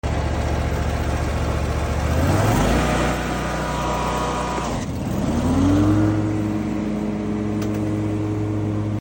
🔊 ASMR: Throttle. Blade. Brake. sound effects free download